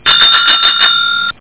BICYCLE.mp3